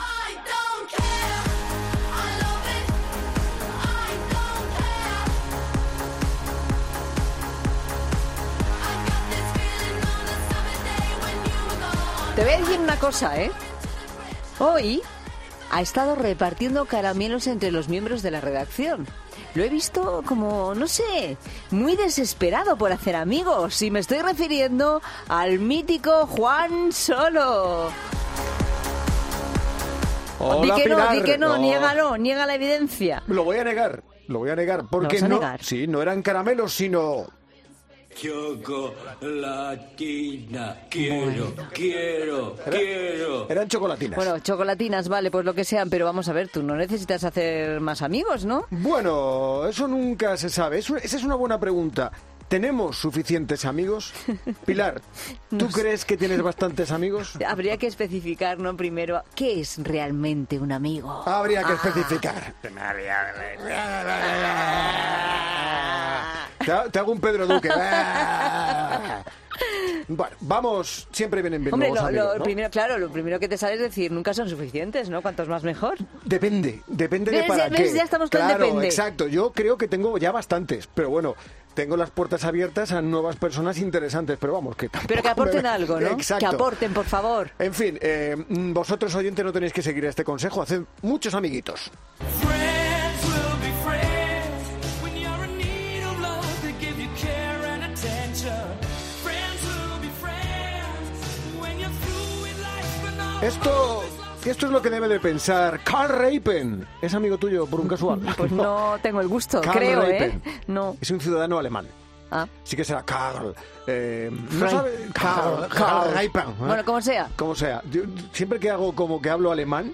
humor atrevido